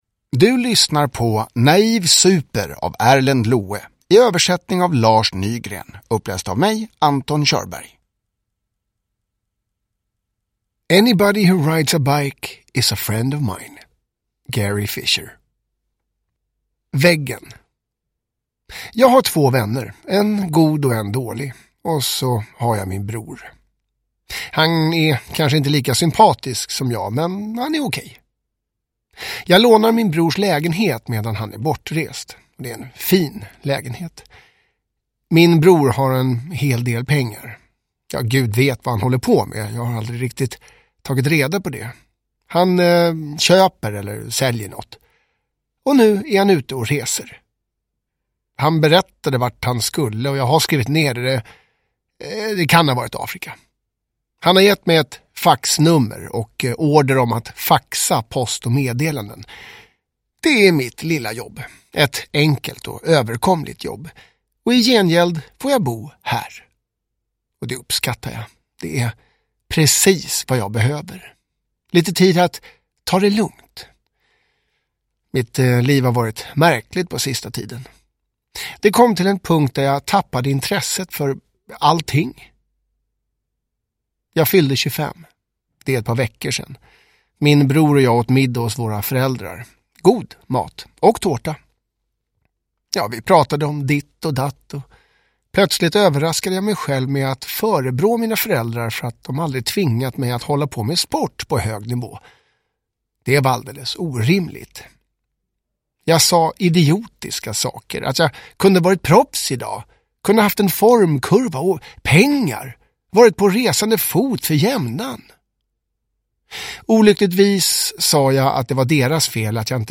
Naiv. Super (ljudbok) av Erlend Loe